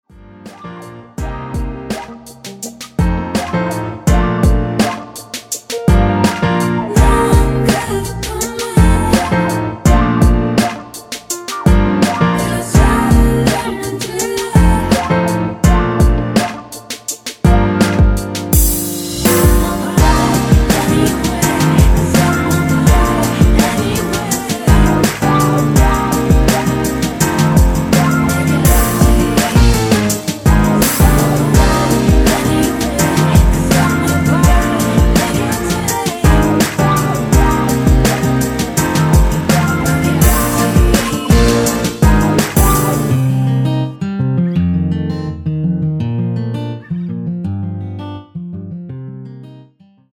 (-2) 내린 코러스 포함된 MR 입니다.(미리듣기 참조)
Bb
앞부분30초, 뒷부분30초씩 편집해서 올려 드리고 있습니다.
중간에 음이 끈어지고 다시 나오는 이유는